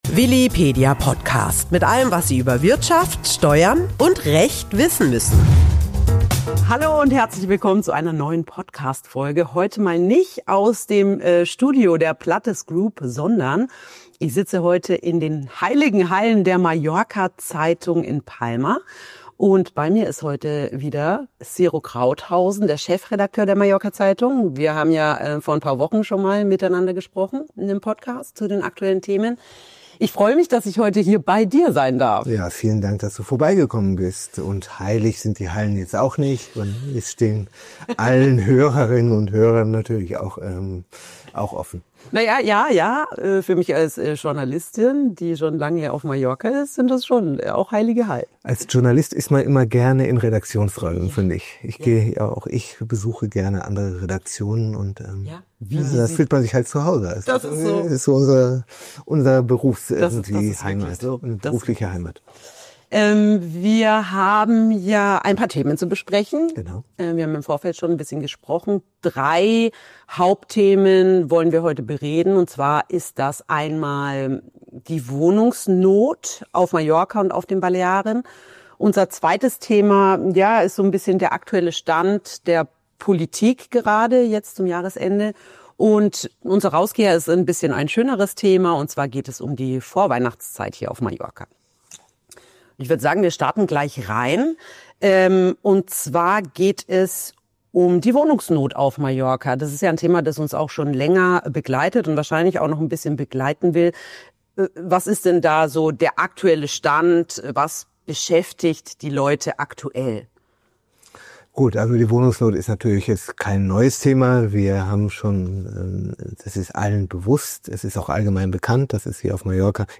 Dieser News-Talk mit der MZ beleuchtet genau diesen Widerspruch. Im Fokus steht die angespannte Wohnraumsituation auf den Balearen.